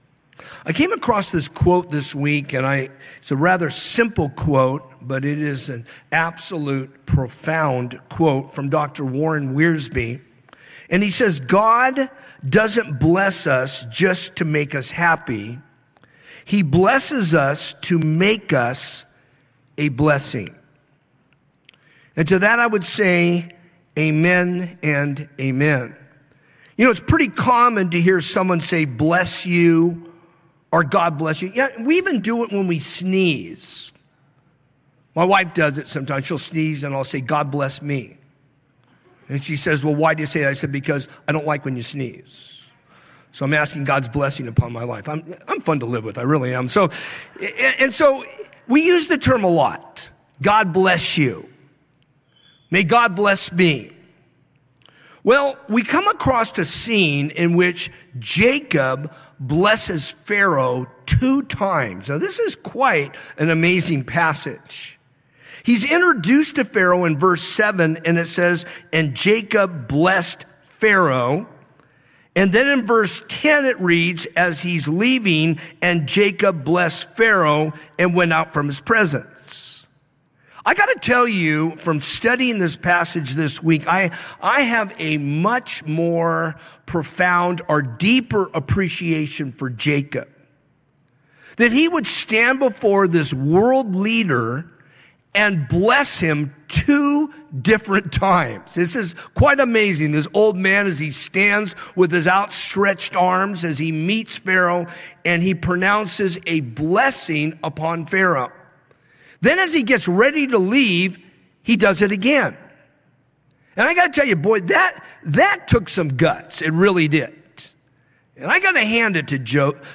A message from the series "In The Beginning…God."
From Series: "Sunday Morning - 10:30"